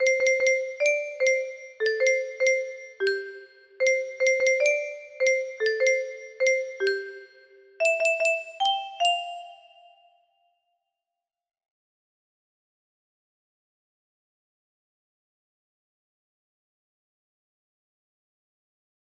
114 music box melody